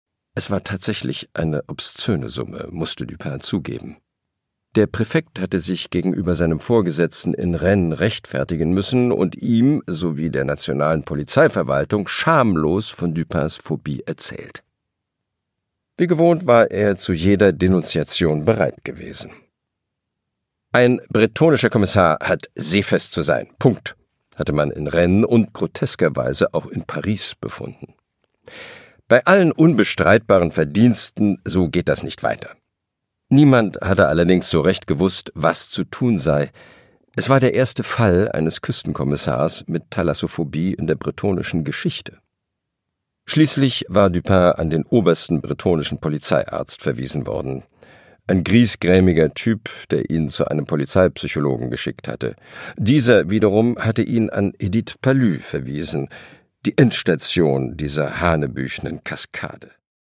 Produkttyp: Hörbuch-Download
Gelesen von: Christian Berkel